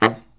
LOHITSAX09-R.wav